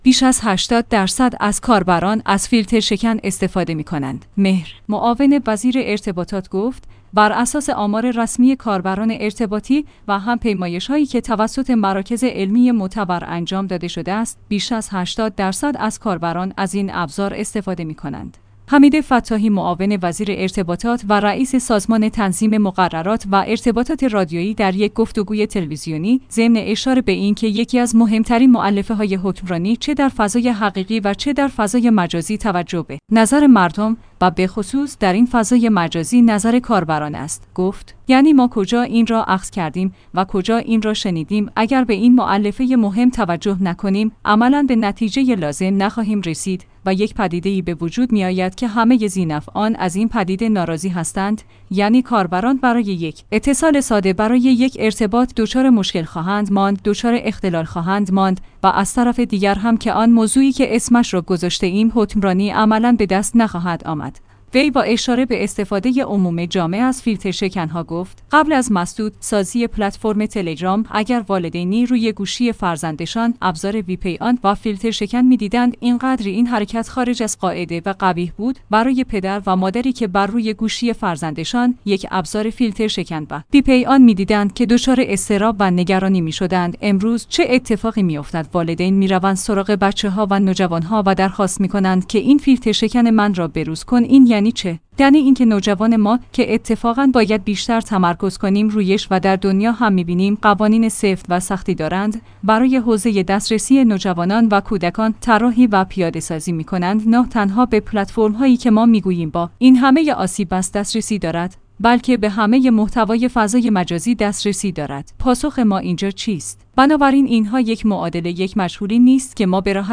حمید فتاحی معاون وزیر ارتباطات و رئیس سازمان تنظیم مقررات و ارتباطات رادیویی در یک گفتگوی تلویزیونی ضمن اشاره به اینکه